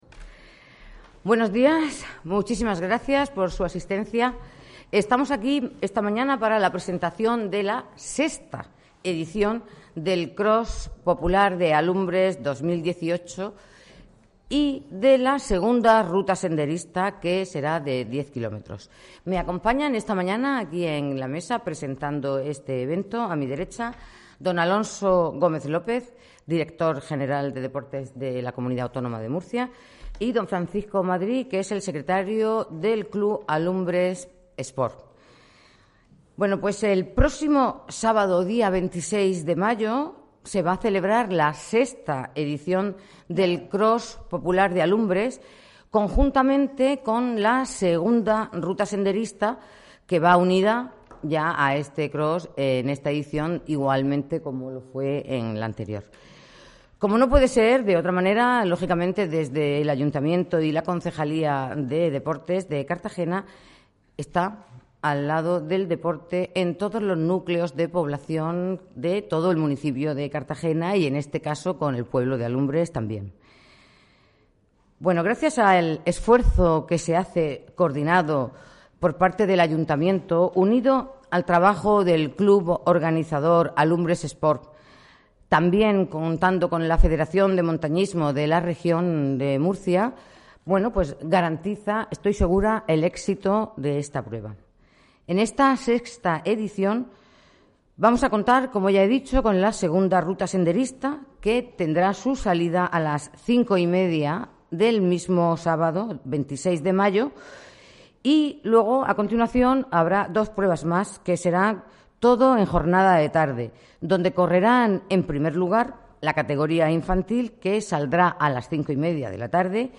La presentación ha tenido lugar hoy, 24 de mayo, de la mano de la concejala de Deportes, Obdulia Gómez